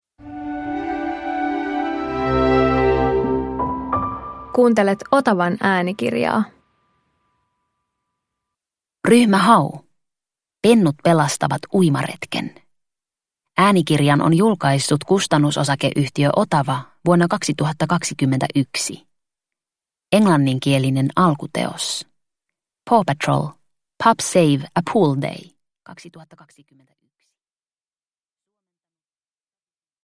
Ryhmä Hau - Pennut pelastavat uimaretken – Ljudbok – Laddas ner
Pentupoppoo seikkailee uudessa äänikirjassa